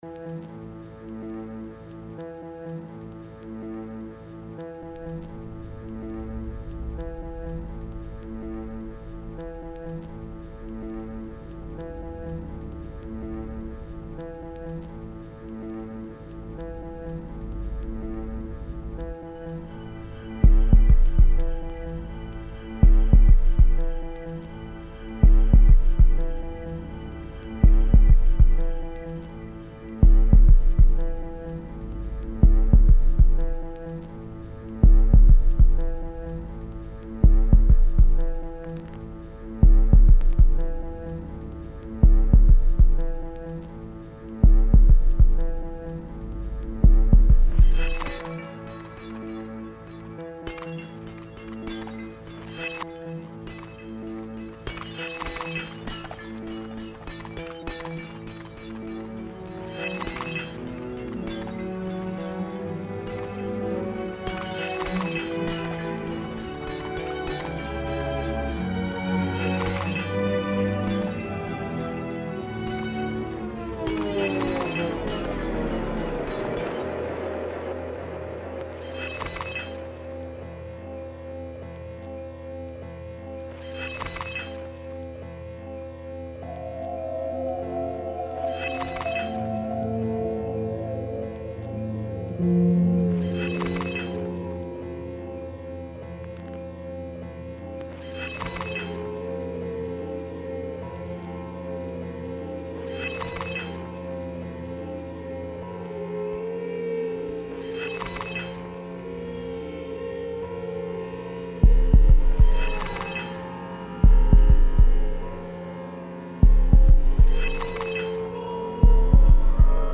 In the audio production, the sound of vases breaking and reassembling is woven into a sound collage around the piece “I know” from the Job Oratorio.
Sound collage